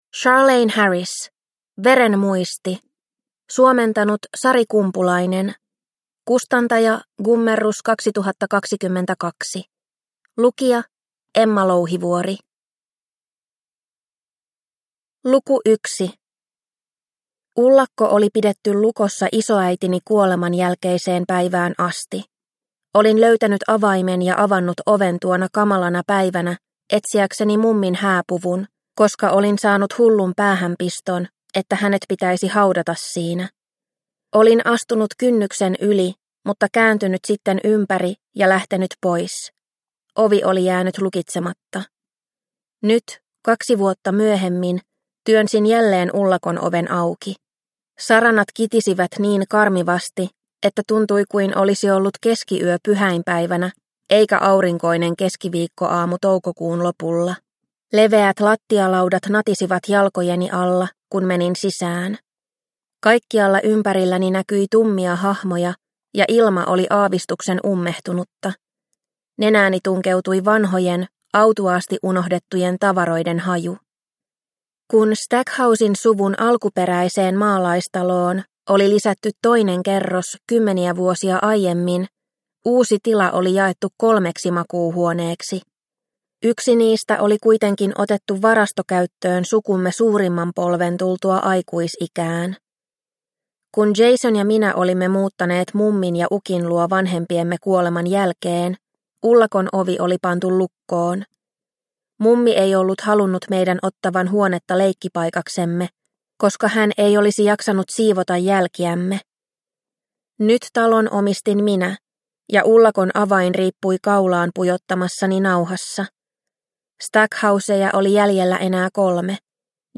Veren muisti – Ljudbok – Laddas ner